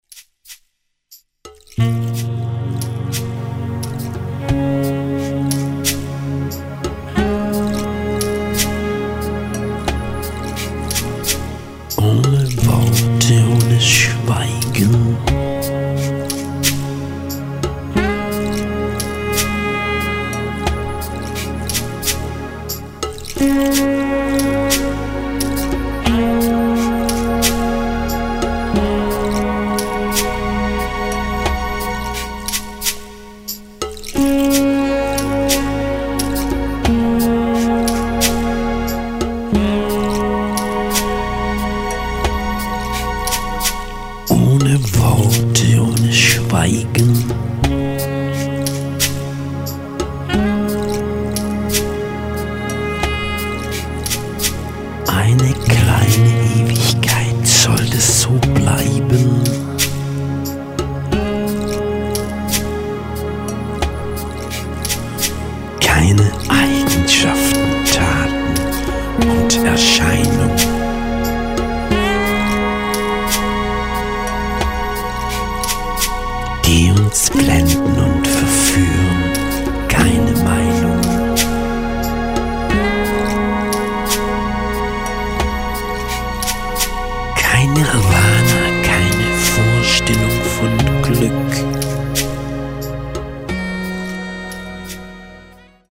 GEMA h y p e r - r o m a n t I c songs and lyrics 1 / 2